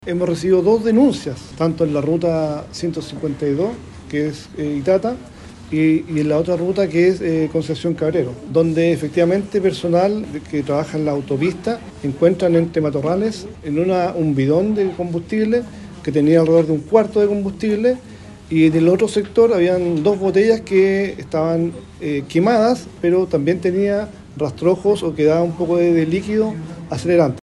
En ese sentido, el general Jefe de Zona de Carabineros Bío Bío, Renzo Miccono, señaló que “hemos recibido dos denuncias, tanto en la Ruta 152 que es el Itata, y la otra ruta, que es Concepción-Cabrero”.